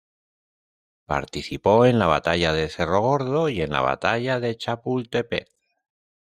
gor‧do
/ˈɡoɾdo/